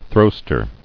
[throw·ster]